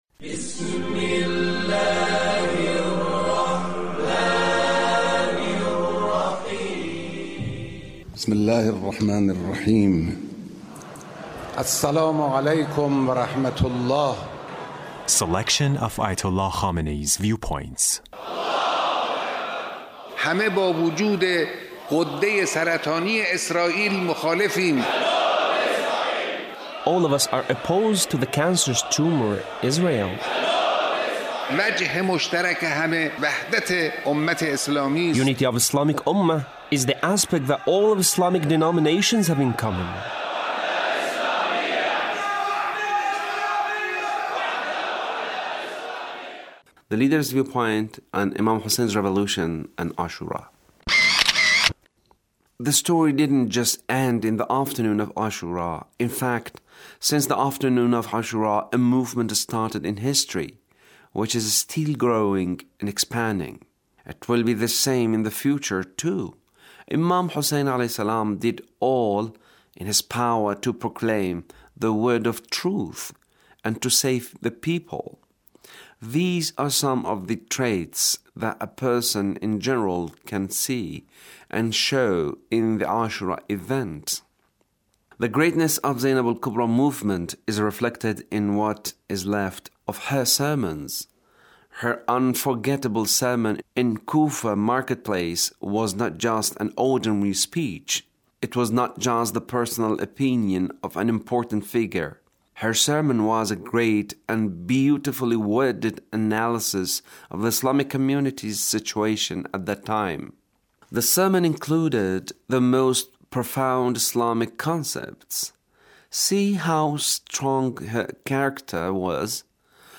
Leader's speech (1473)